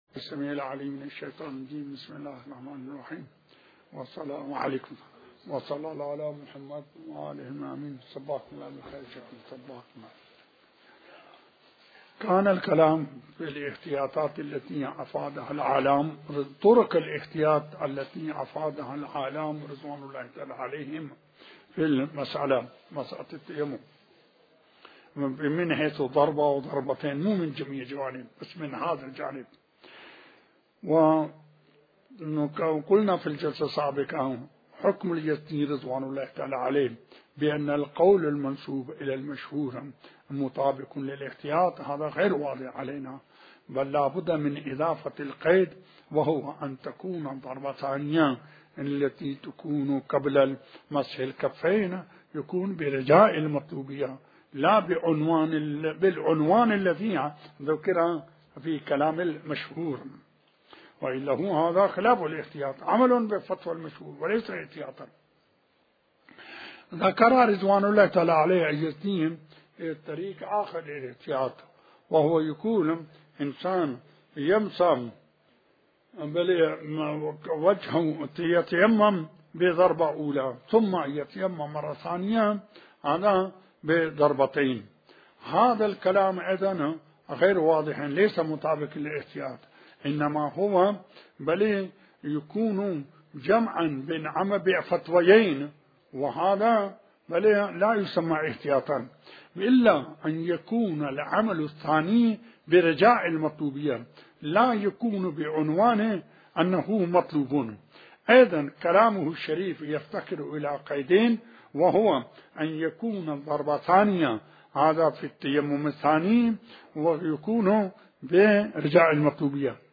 تحمیل آیةالله الشيخ بشير النجفي بحث الفقه 38/04/18 بسم الله الرحمن الرحيم الموضوع : التيمم _ شرائط التيمم - (مسألة 18) : ...